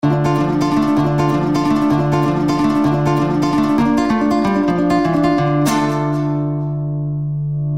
• Качество: 128, Stereo
гитара
короткие
Прикольный рингтон смс для вашего телефона